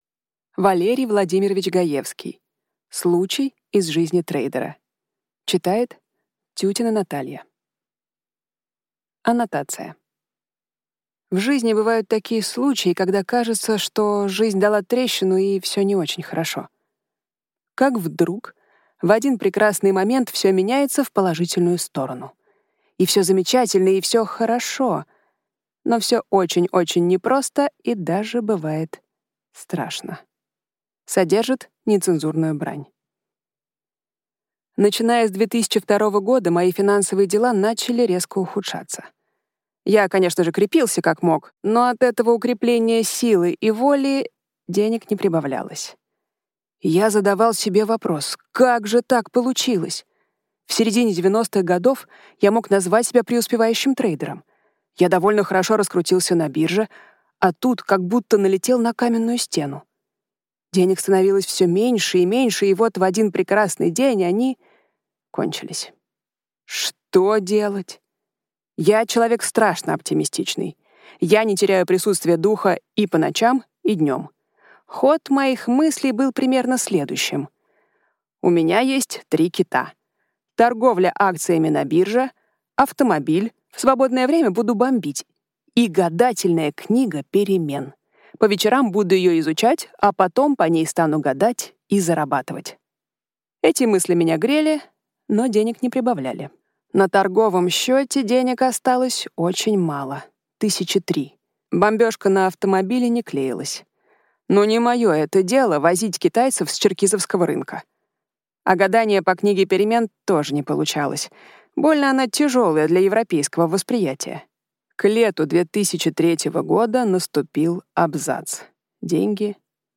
Аудиокнига Случай из жизни трейдера | Библиотека аудиокниг
Прослушать и бесплатно скачать фрагмент аудиокниги